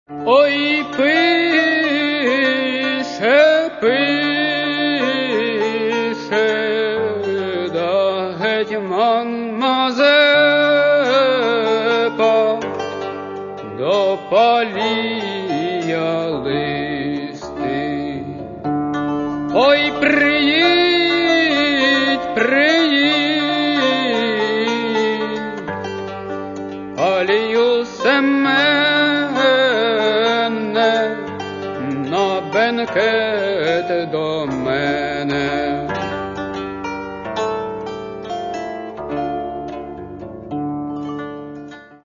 Catalogue -> Folk -> Bandura, Kobza etc